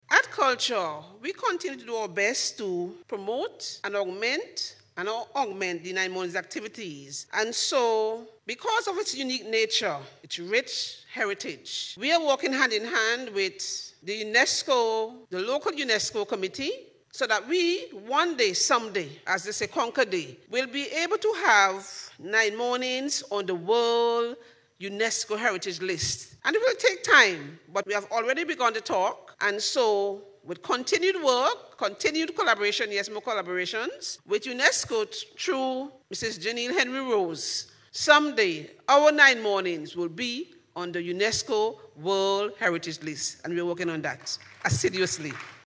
In her address, during the 2025 Nine Morning Award and Prize Giving Ceremony last Saturday, Browne emphasized the cultural significance and unique heritage of the Nine Mornings festival, highlighting ongoing collaboration with the local UNESCO committee.